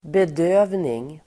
Uttal: [bed'ö:vning]